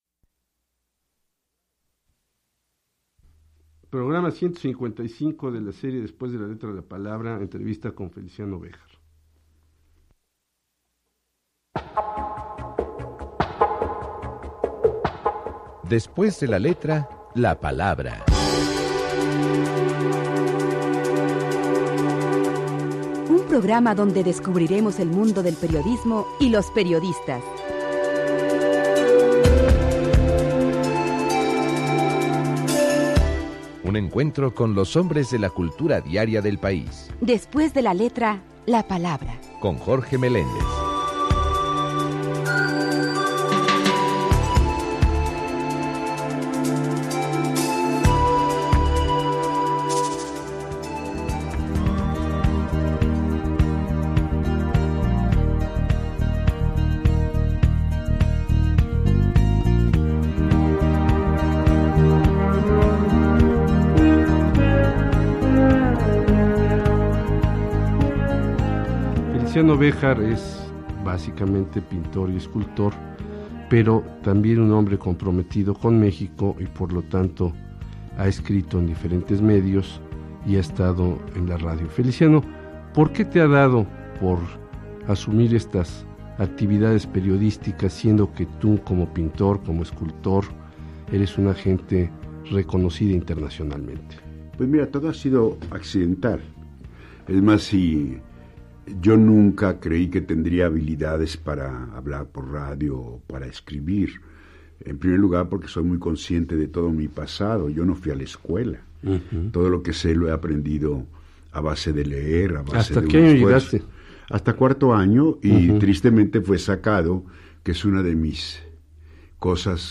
Escucha a Feliciano Béjar entrevistado